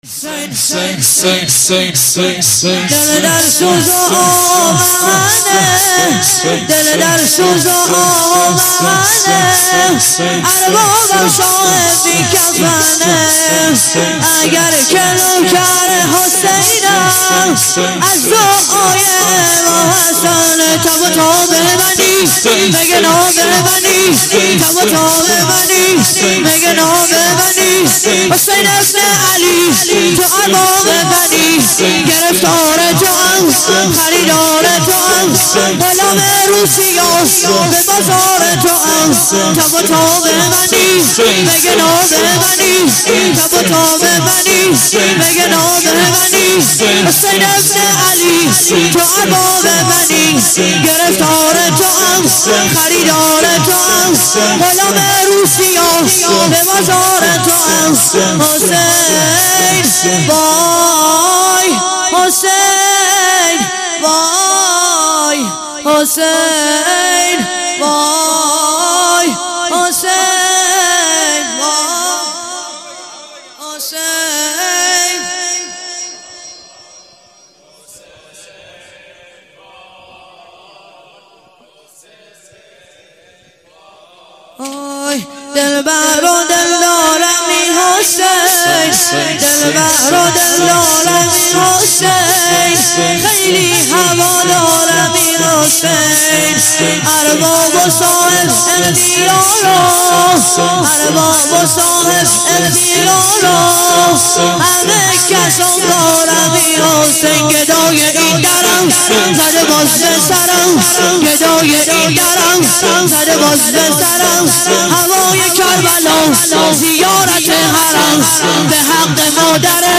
شور - دل در سوز و آه و محنه اربابم شاه بی کفنه